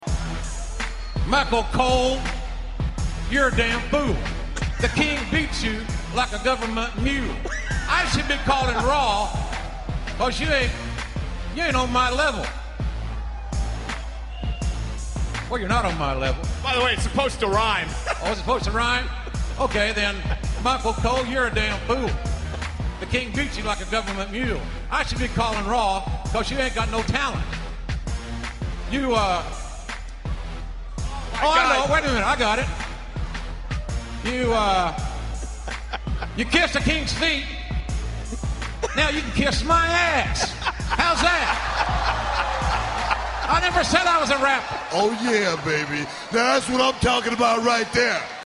RAP BATTLE!